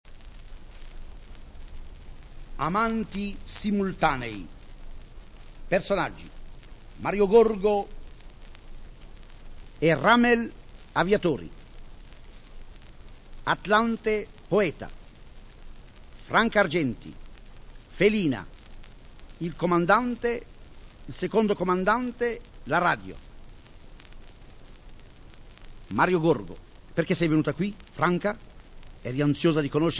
Amanti simultanei : Romanzo futurista discato / Marinetti ; S.E. Marinetti, dizione
• Marinetti, Filippo Tommaso [interprete]
• recitazione
• registrazione sonora di parlato